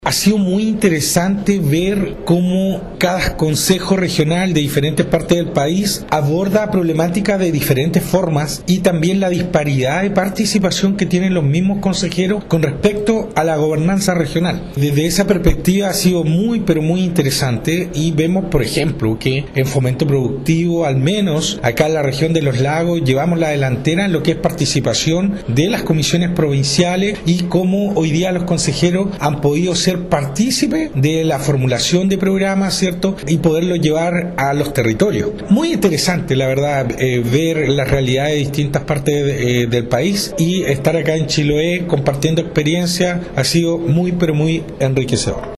Por su parte del Consejero Regional, Andrés Ojeda, también se mostró satisfecho con la actividad realizada en Castro, donde los consejeros pudieron compartir experiencias de trabajo y analizar las diferencias en torno a las gobernanzas regionales: